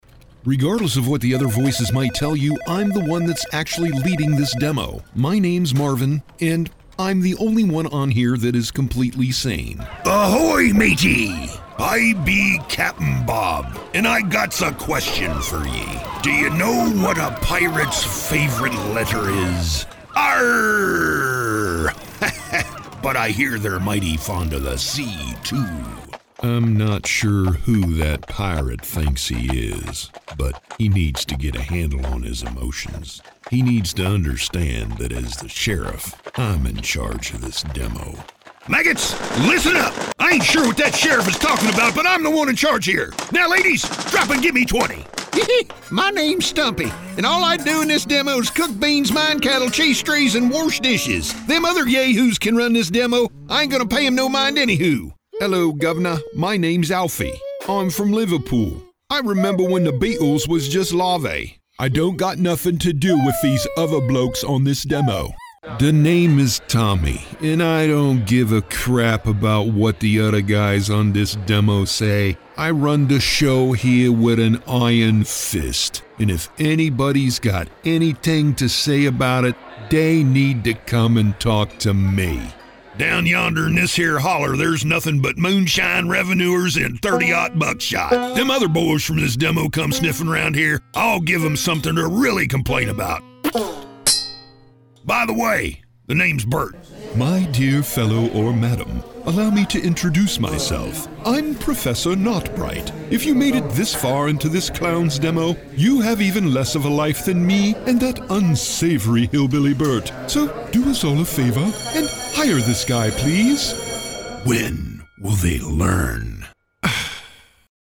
Male
I project grit, gravitas, charisma.
Character / Cartoon
Words that describe my voice are Narrator, Gritty, Conversational.
All our voice actors have professional broadcast quality recording studios.